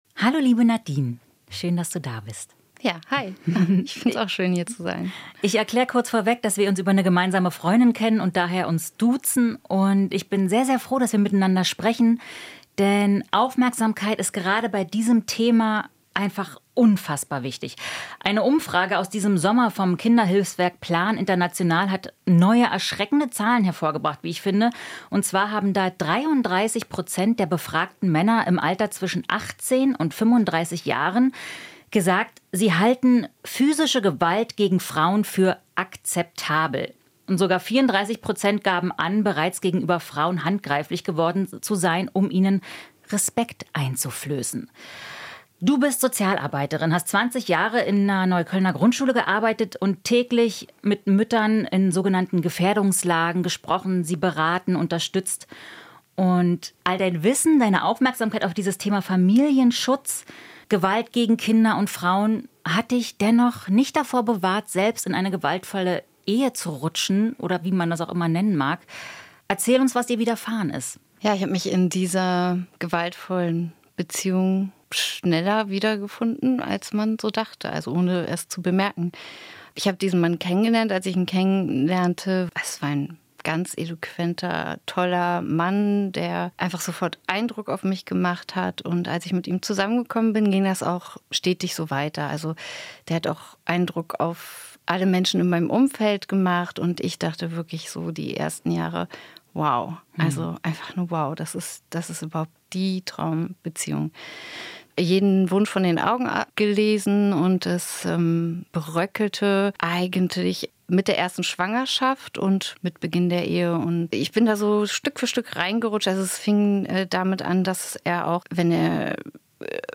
Gewalt gegen Frauen: Eine Betroffene erzählt